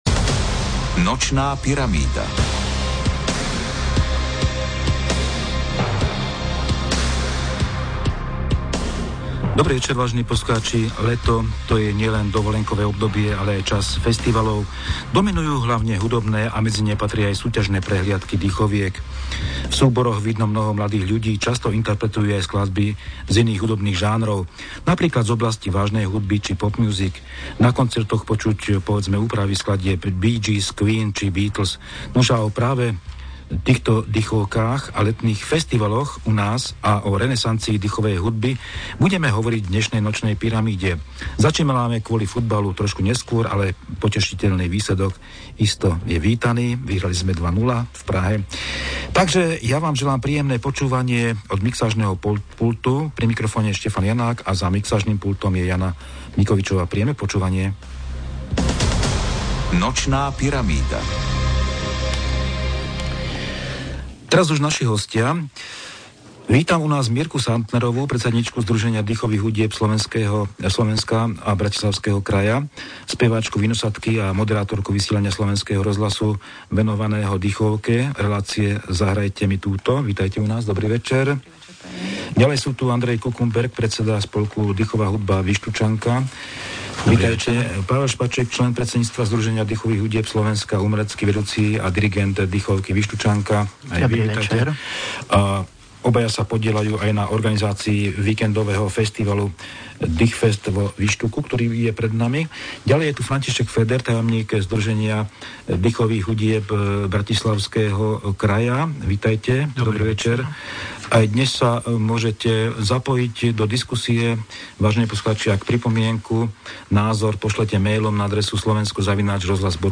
Tu v celoslovenskom vysielaní Rádia Slovensko 1 budú naživo diskutovať a odpovedať na telefonáty poslucháčov na témy: letné festivaly dychovej hudby, minulosť, súčasnosť a budúcnosť dychoviek na Slovensku.